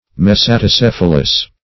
Mesaticephalous \Mes`a*ti*ceph"a*lous\, a.